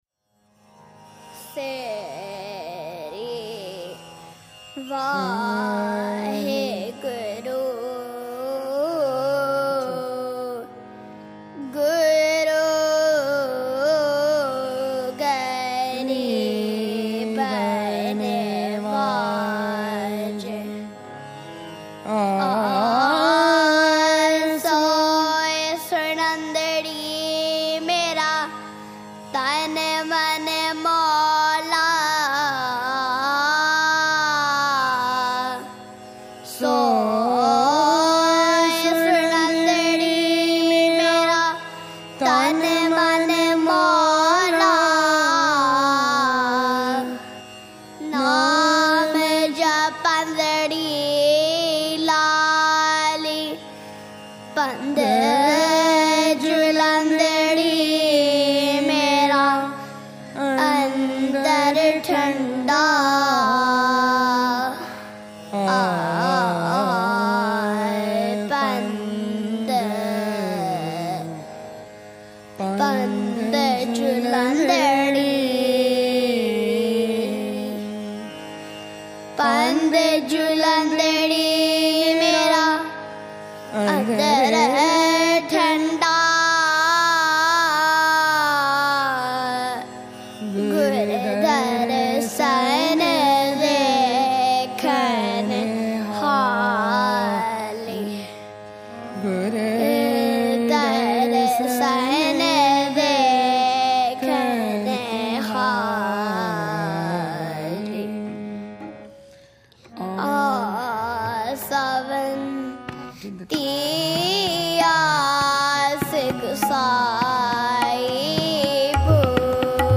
High Definition recordings of contemporary Gurmat Sangeet
at Scarborough Gurdwara on May 21 2011